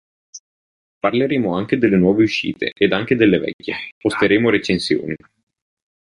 Uitgesproken als (IPA)
/ˈvɛk.kje/